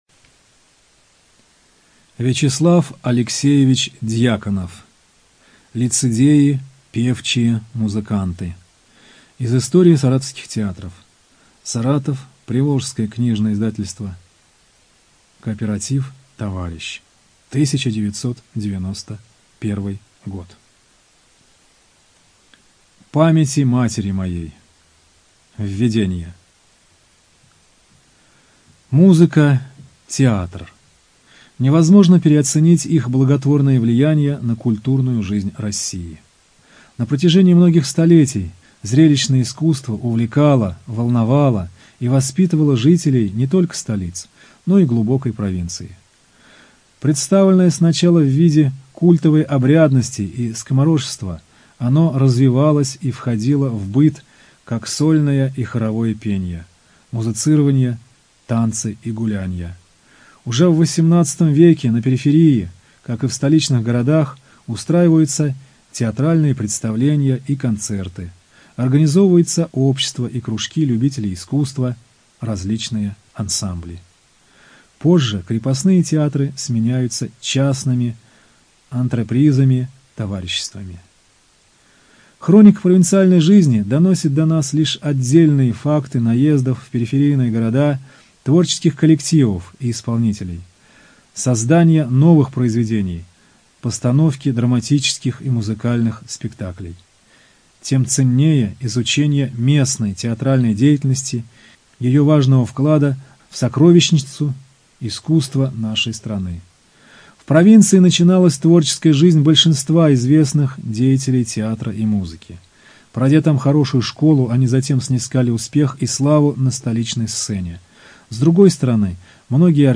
Студия звукозаписиСаратовская областная библиотека для слепых